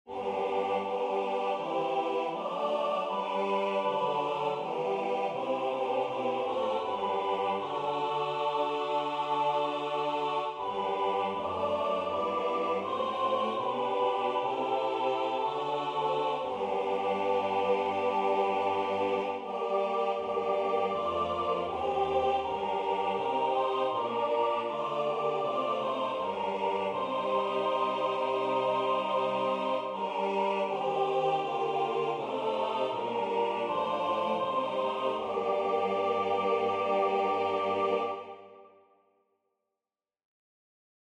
4-part Negative Harmony Example 1 – Aus Meines Herzens Grunde.